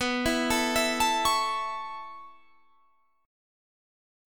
B7sus2sus4 chord